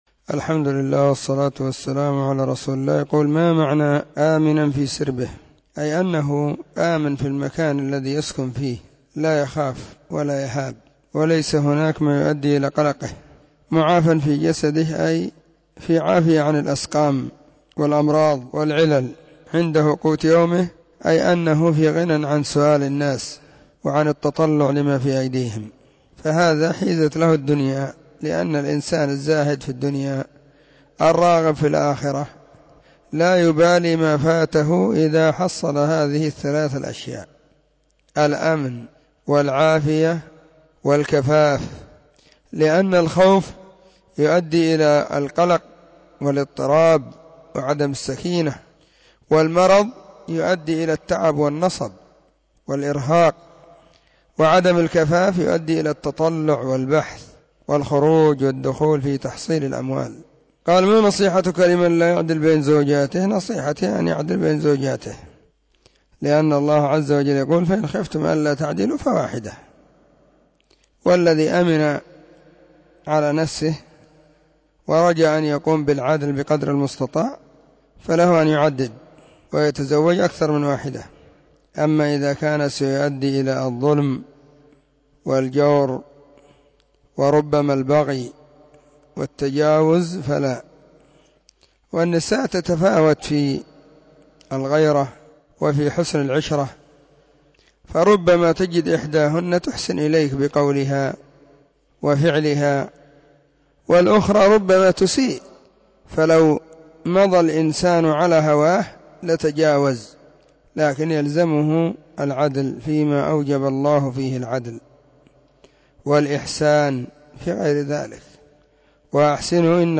فتاوى الإثنين 10 /ربيع الثاني/ 1443 هجرية. ⭕ أسئلة ⭕ -5